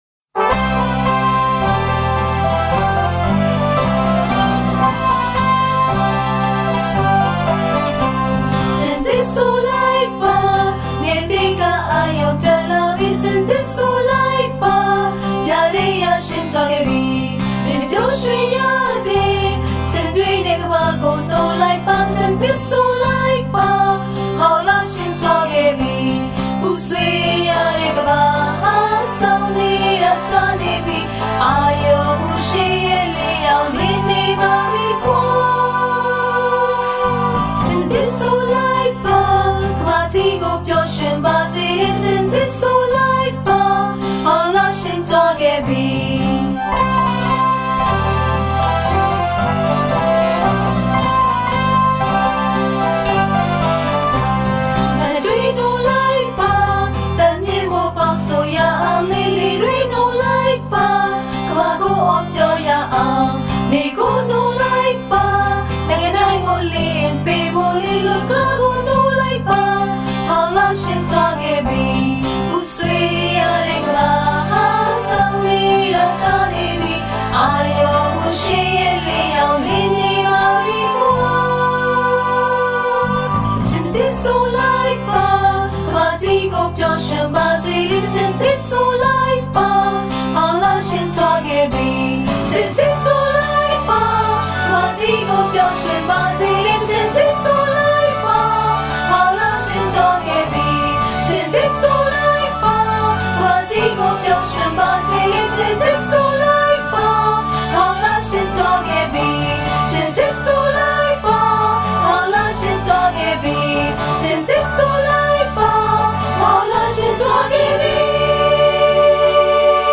Bahá’í Children's Songs
Recorded in Yangoon, Myanmar (2009)
Lead guitars